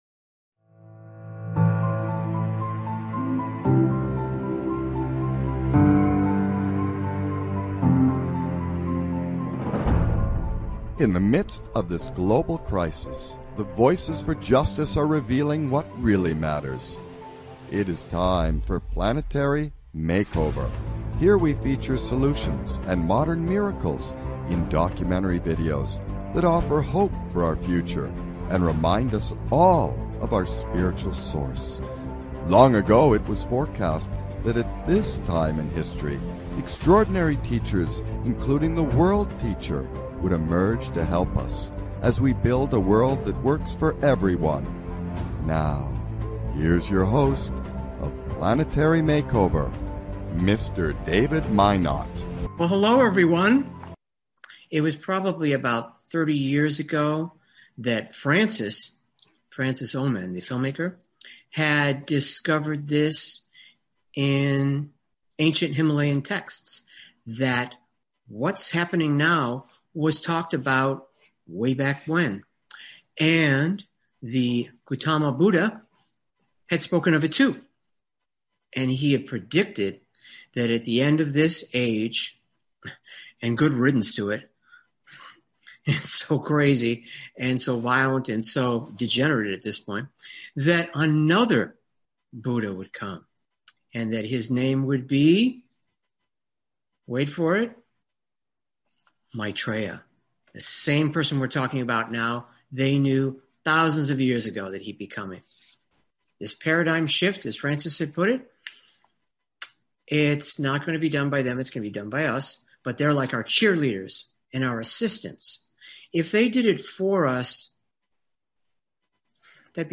Talk Show
So be prepared to call in and share your views and questions, in another uplifting episode of Planetary MakeOver!